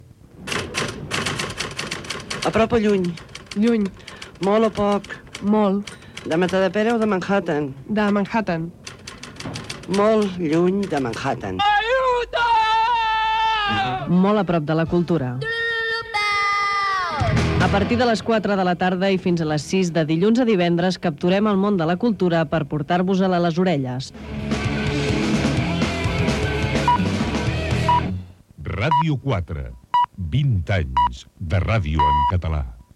Promo del programa i indicatiu dels 20 anys de Ràdio 4 amb els senyals horaris.